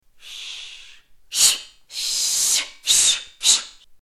Female shhhh sound
Tags: Hush Shhhh Shush Shhhh sounds Hush sounds